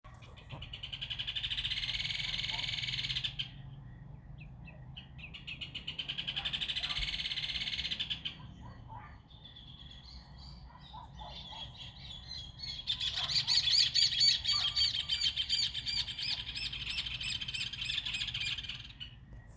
Rufous Hornero (Furnarius rufus)
Country: Argentina
Detailed location: Reserva mocoreta
Condition: Wild
Certainty: Observed, Recorded vocal